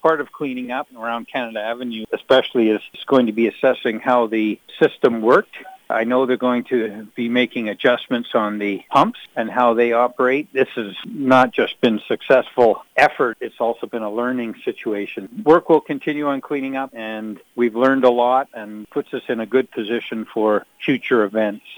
Some families in the Cowichan Valley are in the process of recovering from the floods and Lefebure says, at the municipal level, this event has taught politicians and staff a lot.